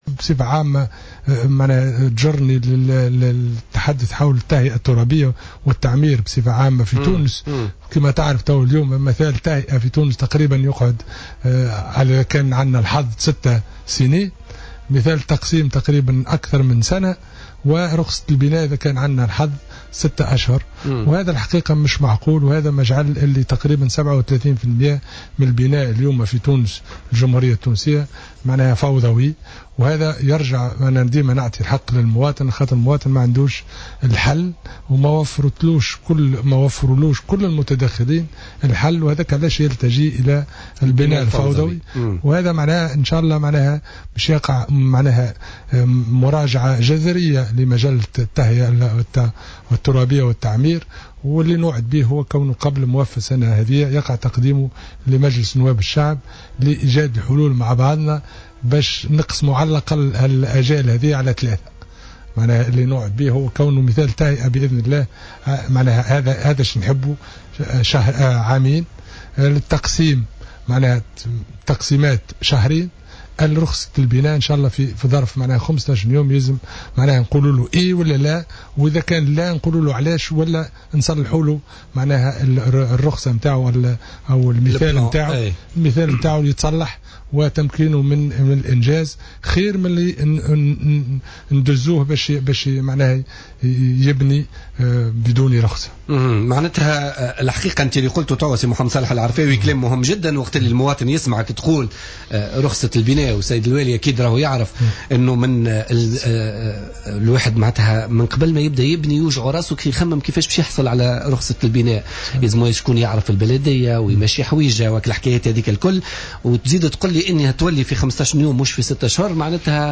تحدث وزير التجهيز والإسكان محمد صالح العرفاوي، ضيف بوليتيكا اليوم الخميس عن إشكاليات التهيئة الترابية والتعمير في تونس والفترة الطويلة التي يتطلبها مثال التهيئة الذي يتجاوز الـ 6 سنوات، في حين يتطلب مثال التقسيم أكثر من سنة، و6 اشهر للحصول على رخصة بناء.